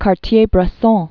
(kär-tyābrĕ-sôɴ), Henri 1908-2004.